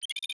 Holographic UI Sounds 80.wav